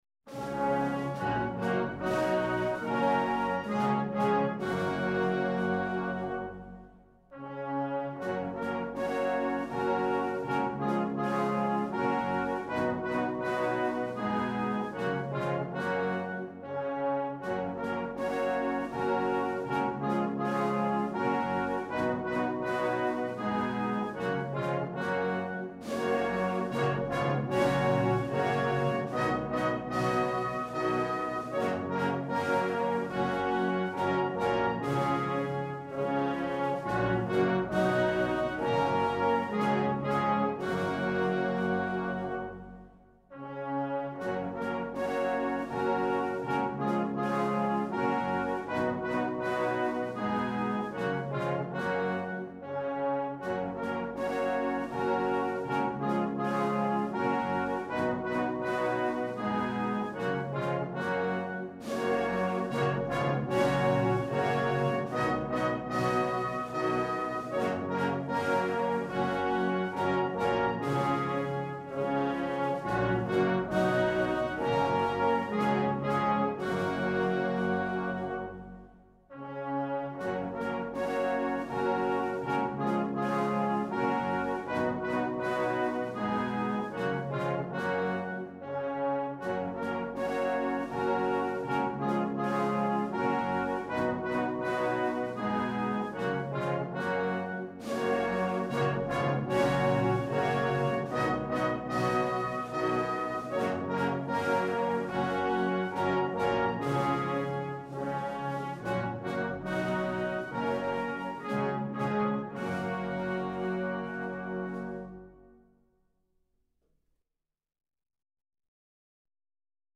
Snare Drum
Band Accomp